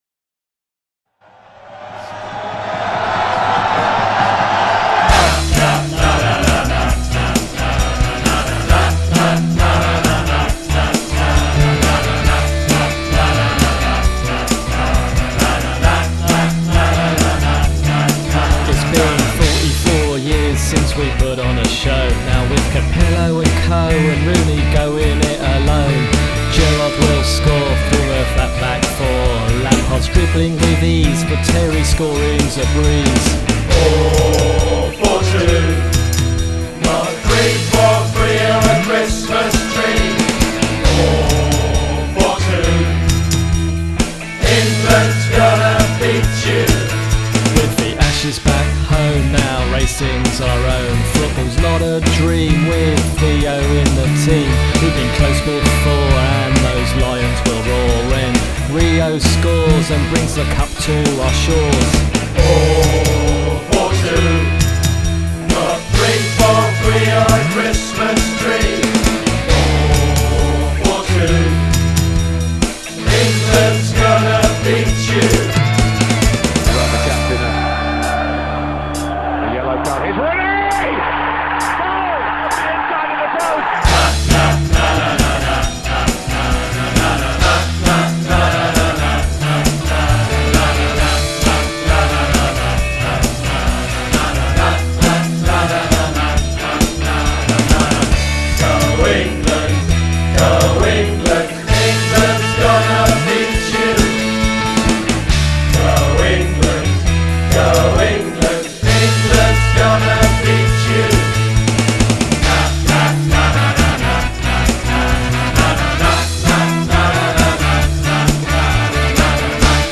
Re-jigged, re-recorded, pumped up and polished for 2010.